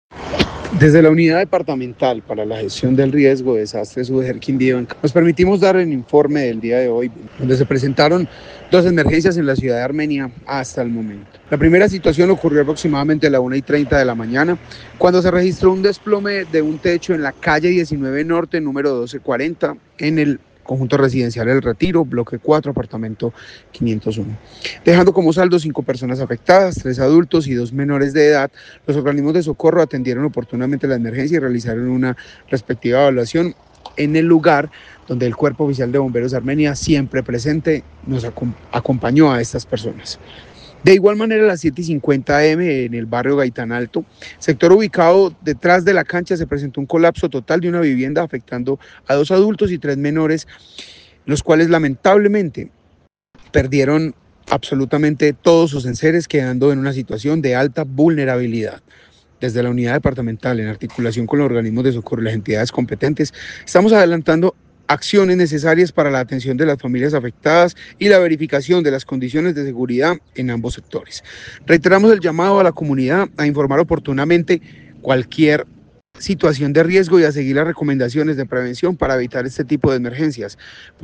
Jaider Hidalgo, director Gestión Riesgo, Quindío